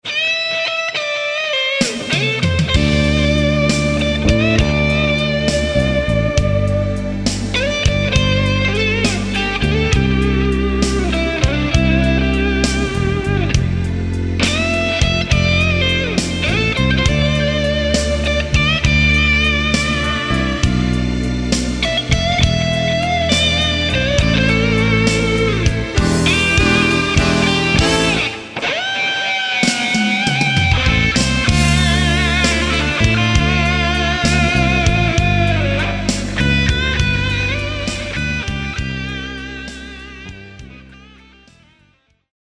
guitars
drums
bass
keyboards